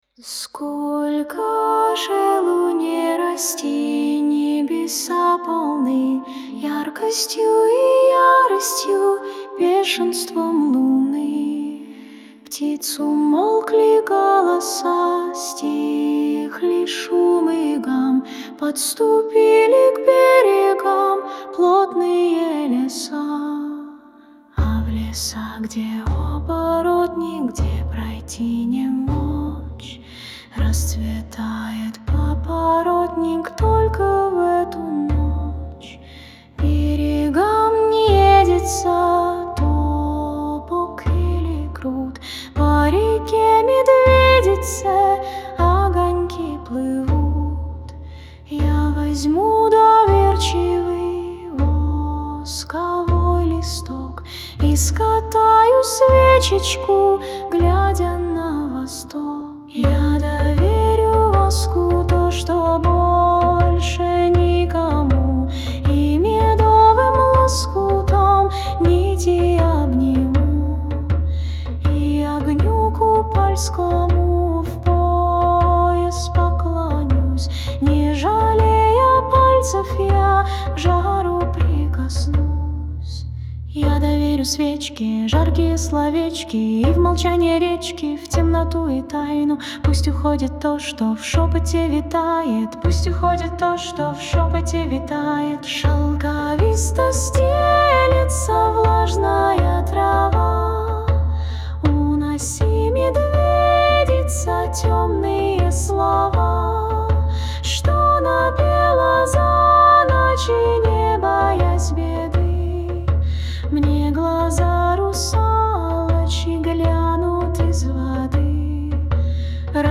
Пронзительная вещь получилась: тихая просьба о чуде окутана тайной и загадочным светом луны.